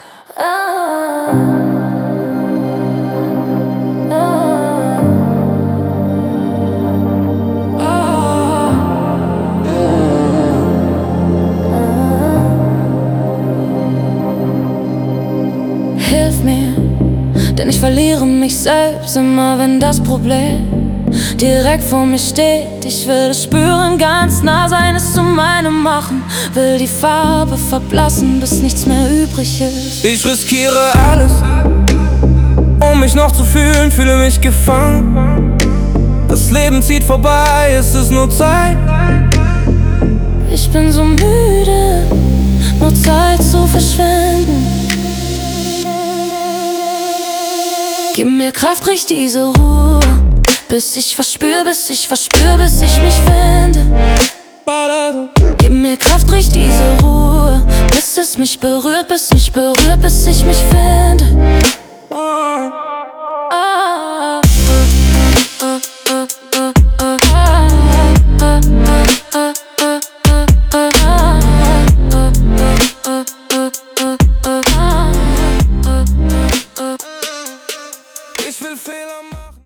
Und wir lieben es, zu zweit zu singen.